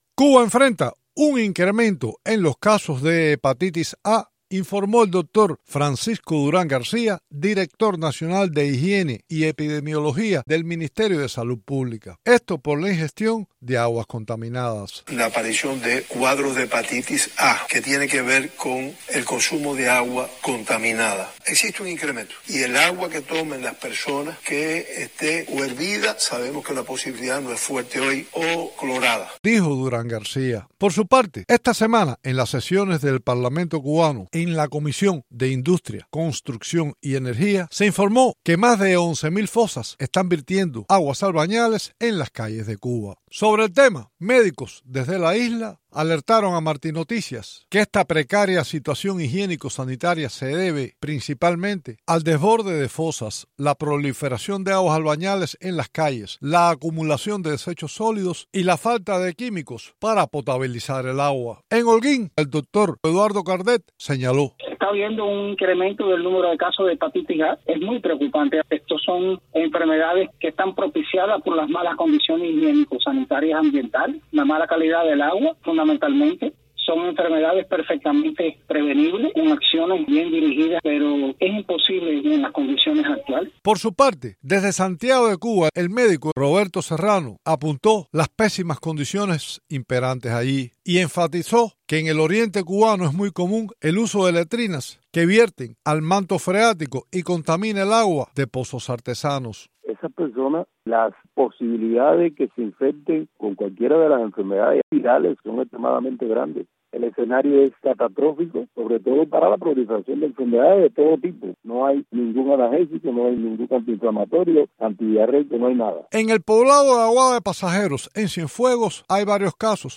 Las autoridade informaron de un brote de hepatitis A debido a la contaminación de las aguas. Cubanos consultados por Martí Noticias explican cómo las fosas están desbordadas.